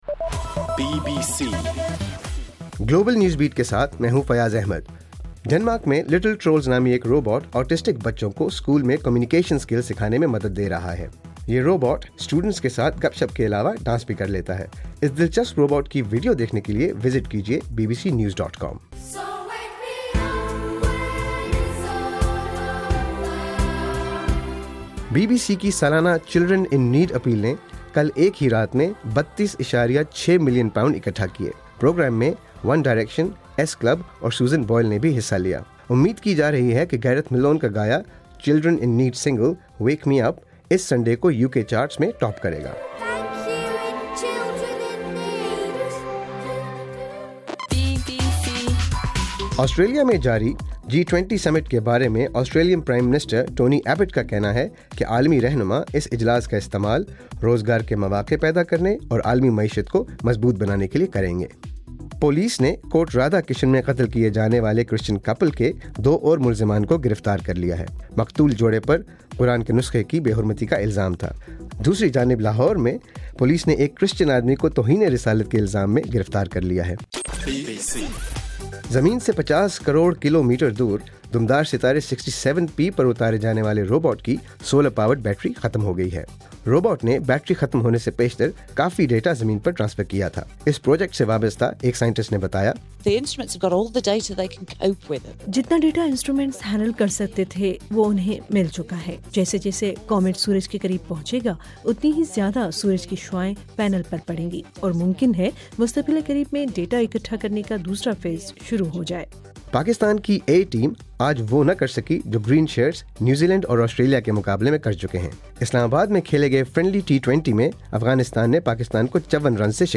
نومبر: رات 8 بجے کا گلوبل نیوز بیٹ بُلیٹن